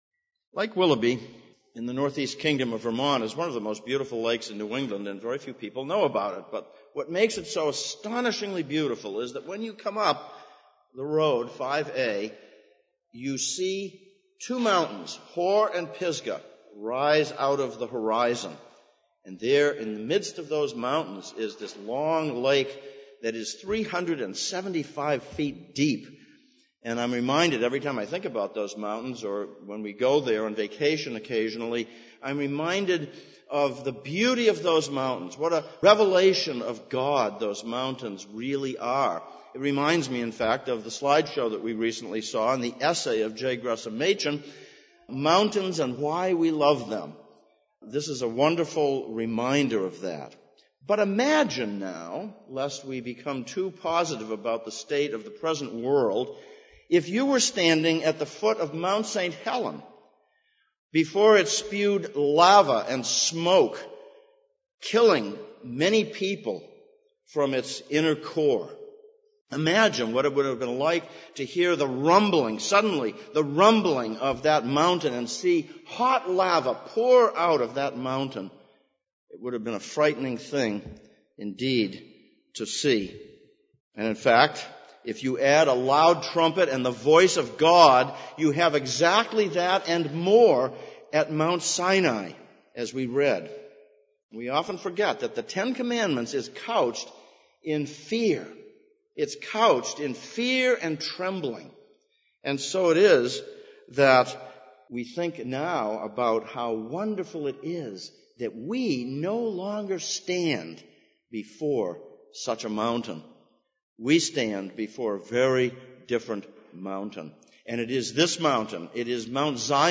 Exodus 20:18-24 Service Type: Sunday Morning « Be Afraid